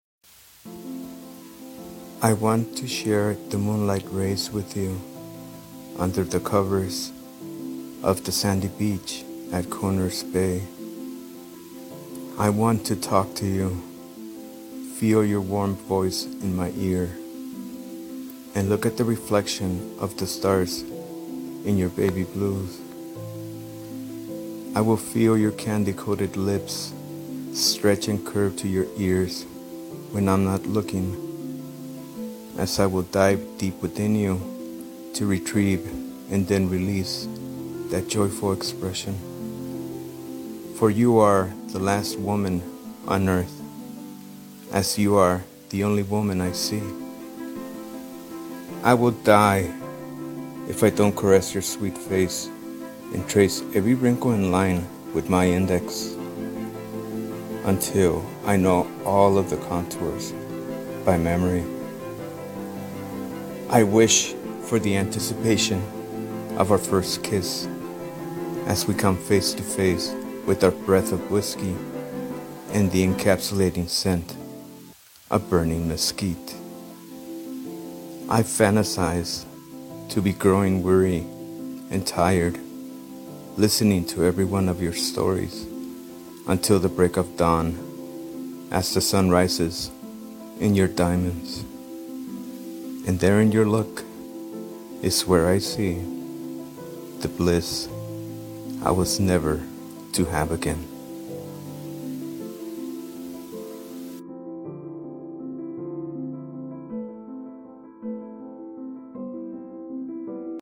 spoken poetry